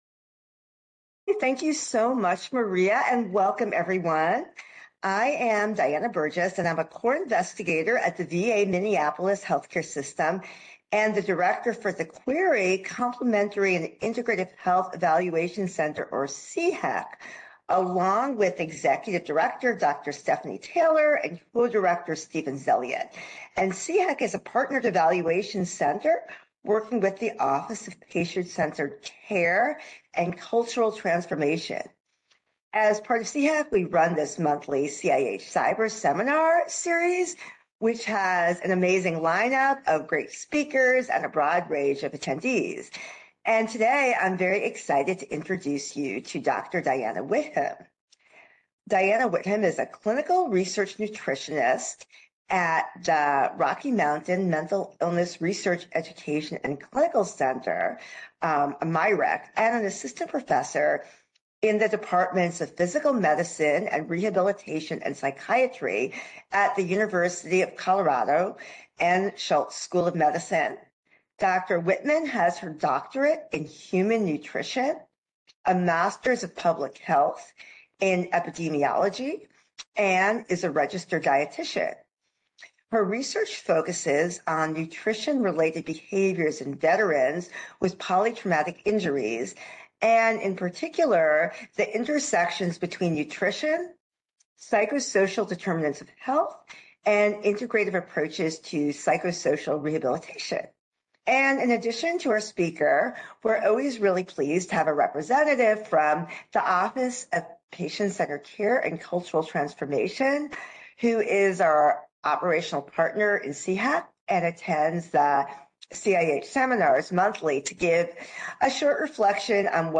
RDN Seminar date